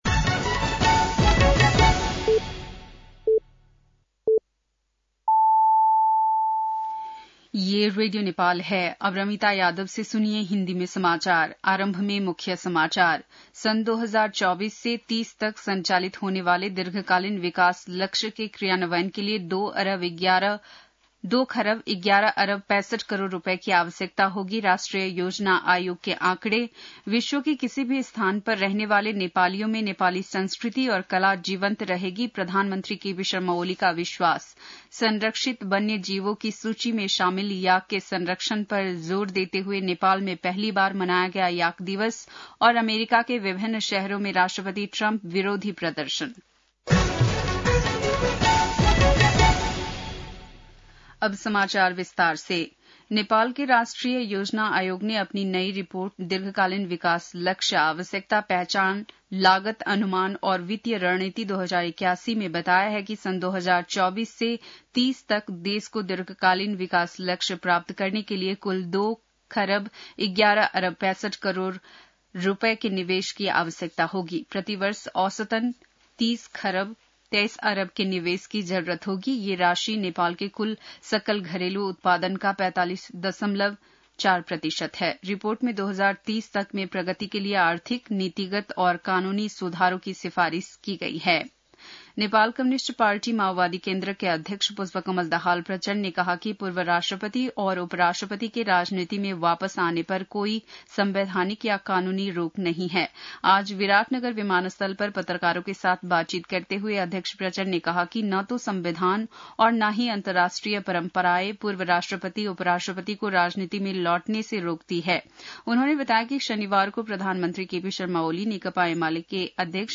बेलुकी १० बजेको हिन्दी समाचार : ७ वैशाख , २०८२
10pm-hindi-news-1-07.mp3